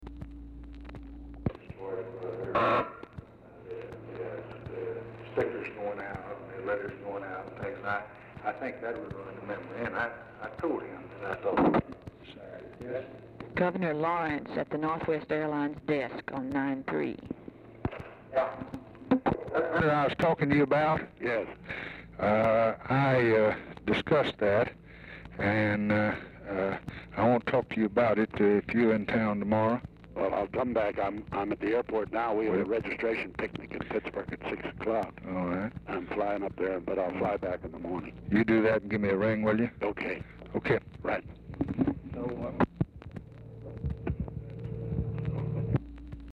OFFICE CONVERSATION PRECEDES CALL
Format Dictation belt
Location Of Speaker 1 Oval Office or unknown location
Specific Item Type Telephone conversation Subject Elections National Politics